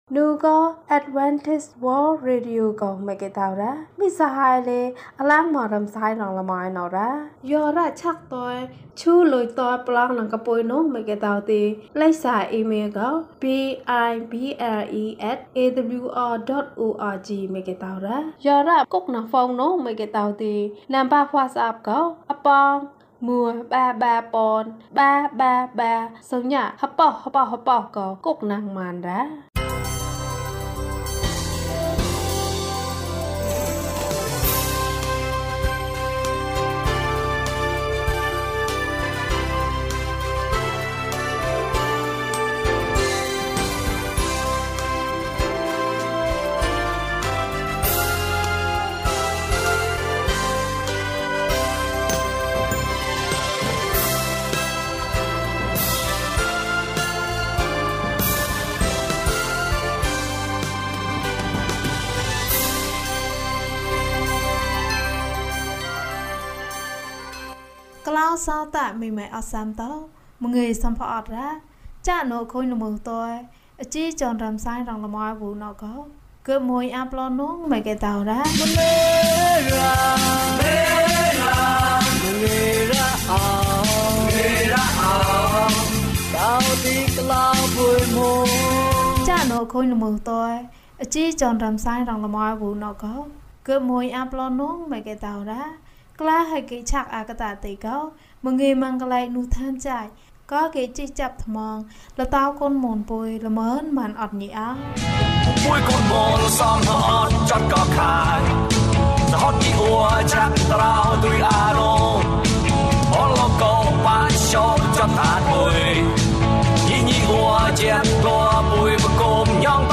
တံငါ။ ကျန်းမာခြင်းအကြောင်းအရာ။ ဓမ္မသီချင်း။ တရားဒေသနာ။